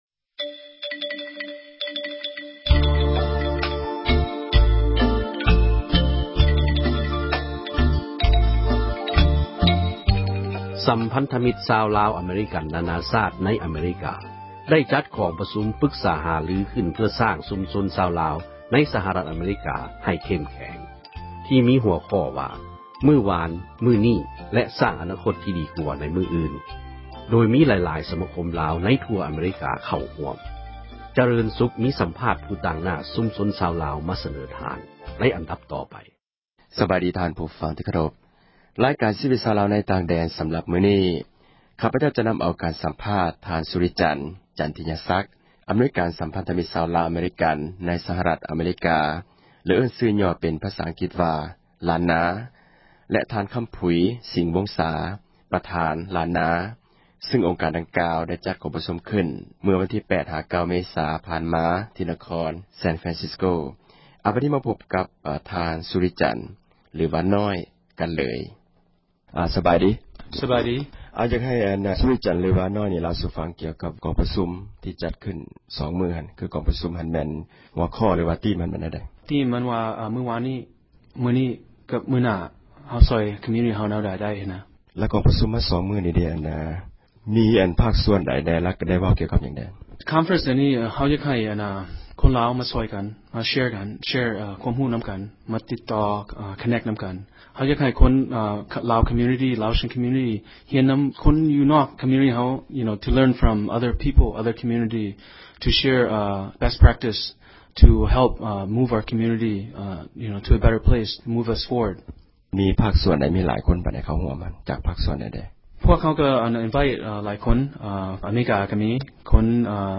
ສັມພາດຜູ້ຕາງໜ້າ ຊຸມຊົນຊາວລາວ